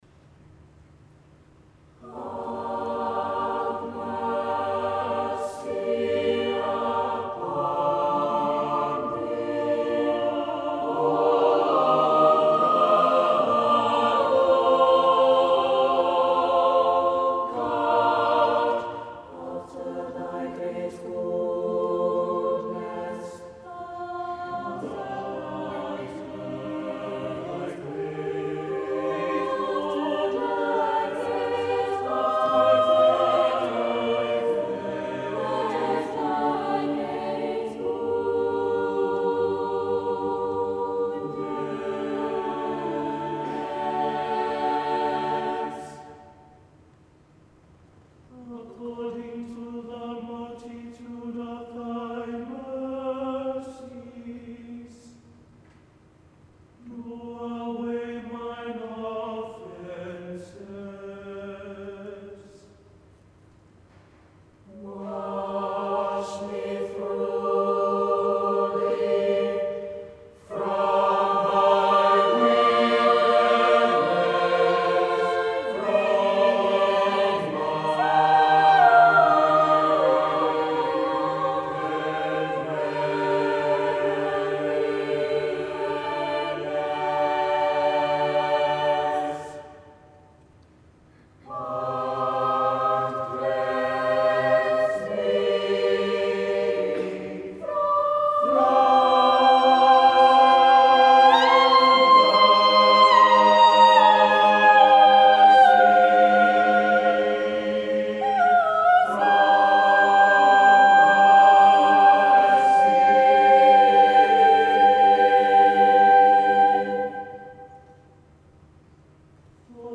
Recordings from The Big Sing National Final.